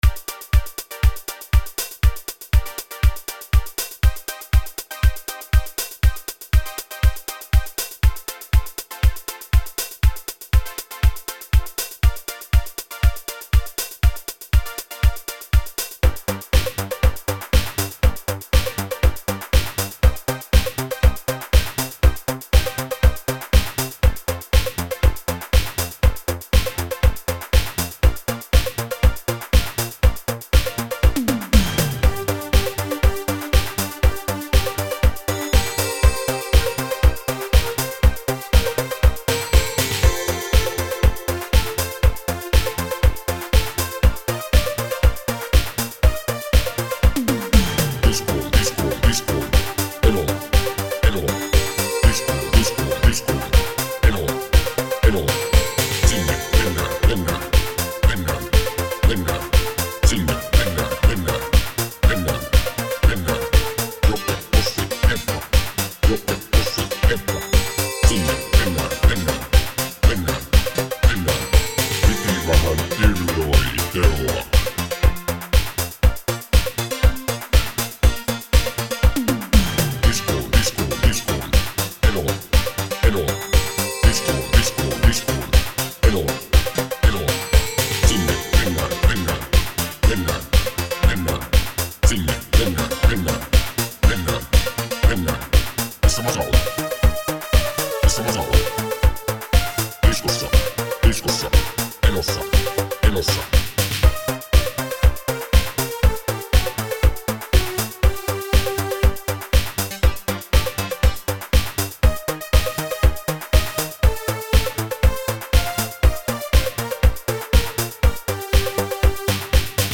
80-luvun italodiskotyylisen coverin